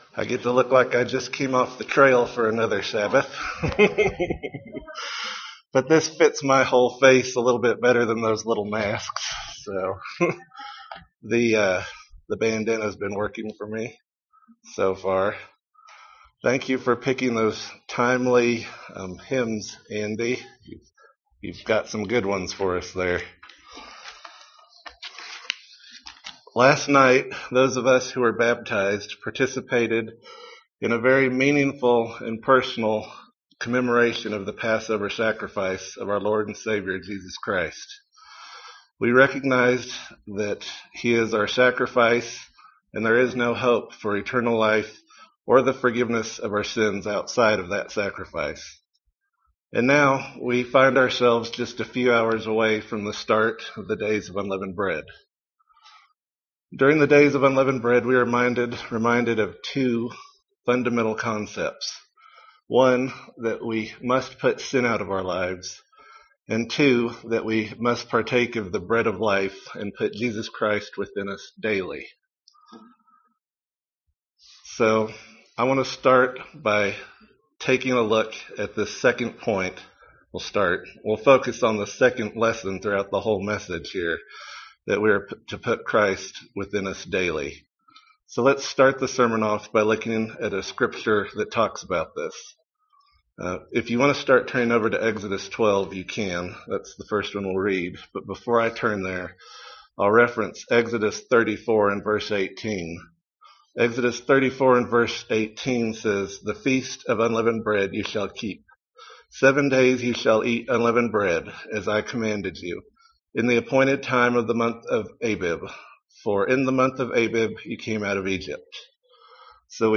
This sermon makes a connection between eating unleavened bread daily during the Day of Unleavened Bread and eating of the Bread of Life daily. We will cover 4 areas we can focus on in becoming more Christ -like.